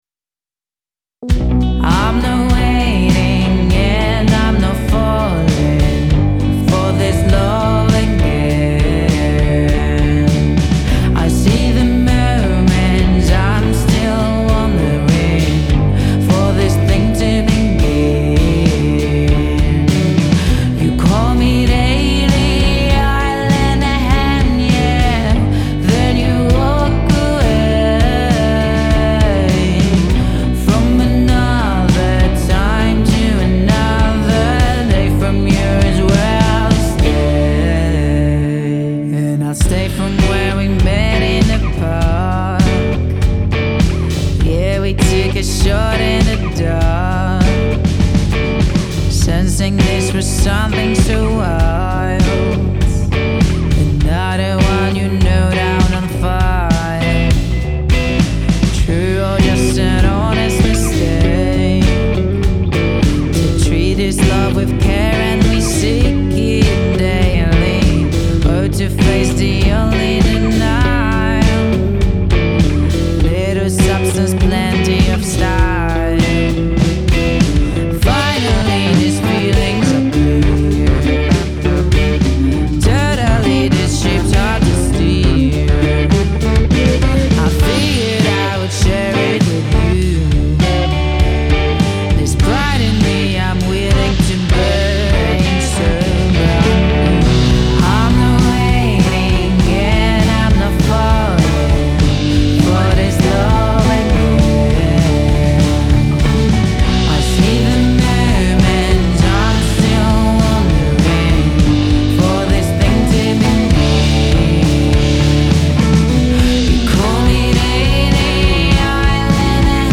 Hey y’all, I need feedback on this track, specifically on the verse section with the synth, and general leveling critique.